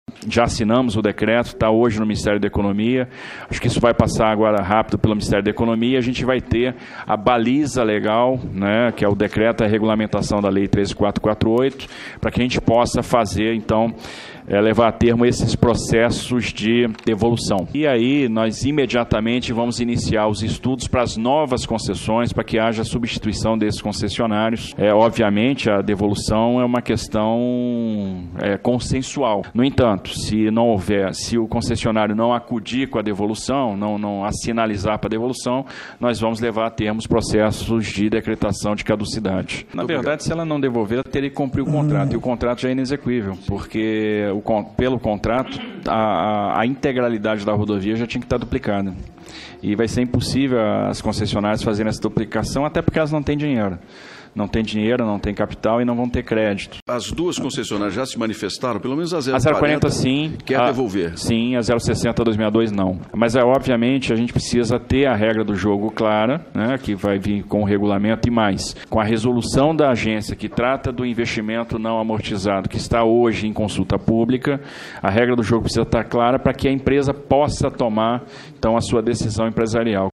Empresas que não cumprem contrato podem perder a concessão das estradas. A afirmação é do ministro da infra-estrutura Tarcísio de Freitas durante audiência pública, nesta semana, no Senado.
Ministro da infra-estrutura Tarcísio de Freitas